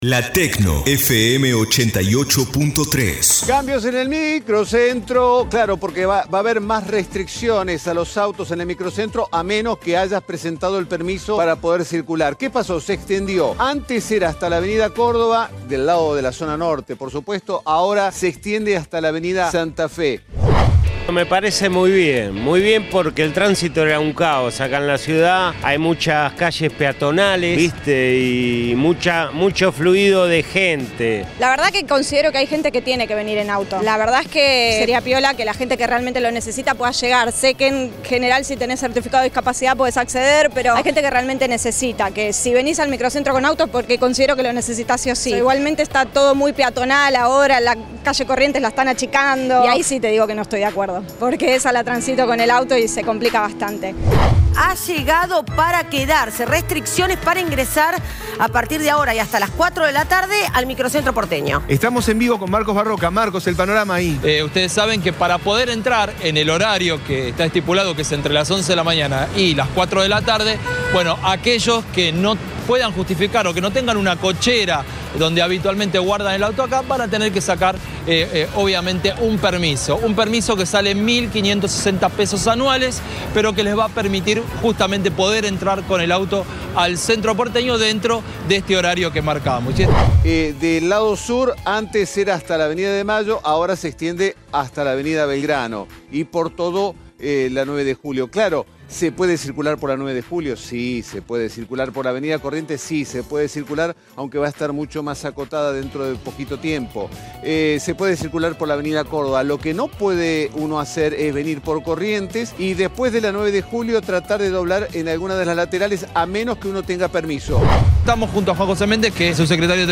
INFORME-CENTRO-PORTE--O-SIN-AUTOS-1.mp3